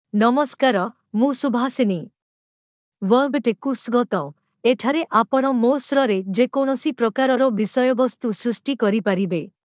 Subhasini — Female Odia AI voice
Subhasini is a female AI voice for Odia (India).
Voice sample
Listen to Subhasini's female Odia voice.
Female
Subhasini delivers clear pronunciation with authentic India Odia intonation, making your content sound professionally produced.